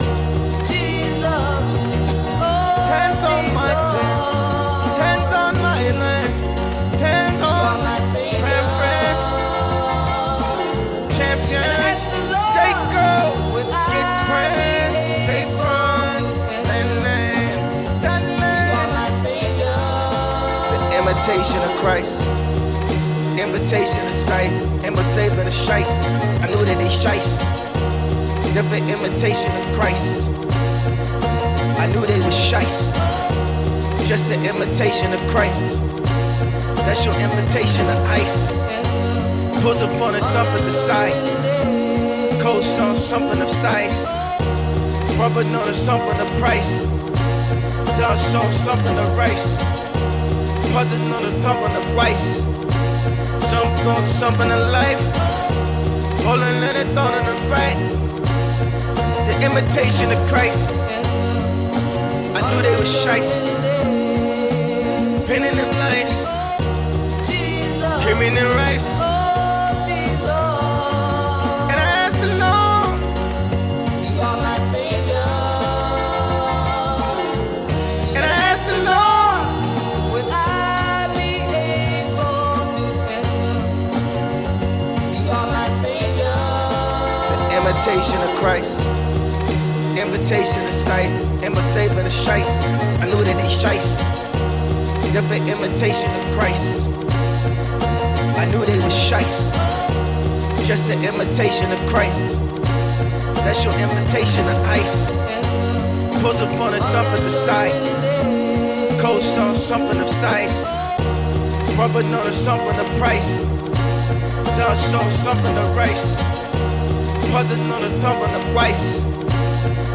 If you ignore the auto-tune yapping